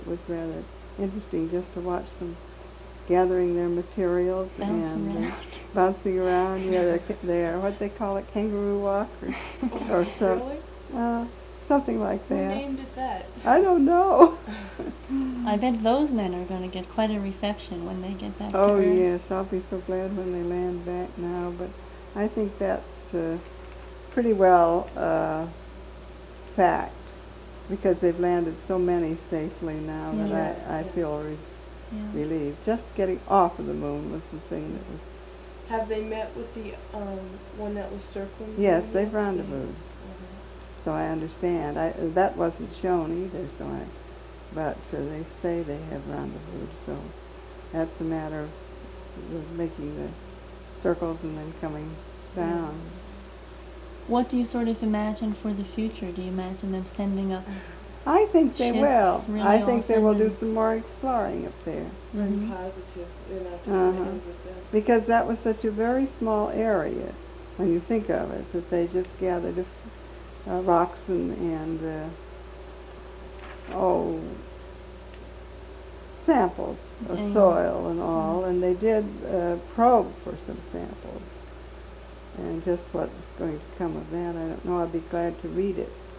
Moon Landing Interview